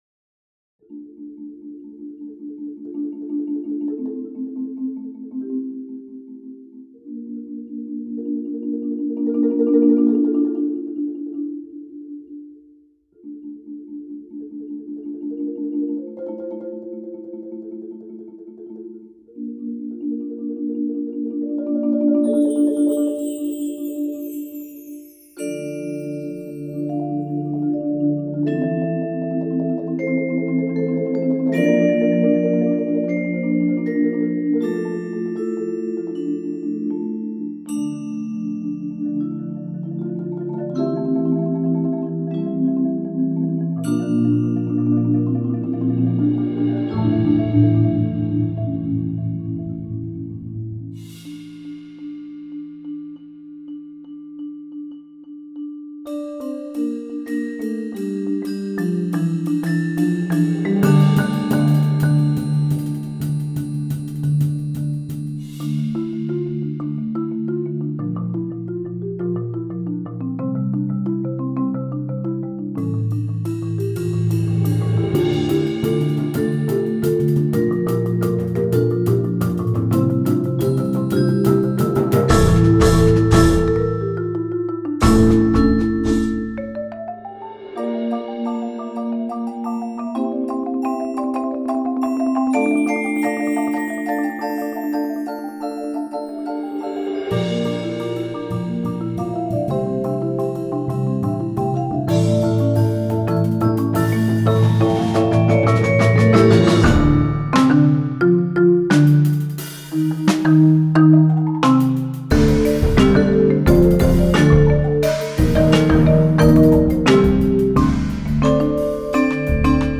Voicing: 11 Percussion